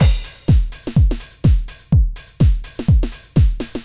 Index of /musicradar/retro-house-samples/Drum Loops
Beat 14 Full (125BPM).wav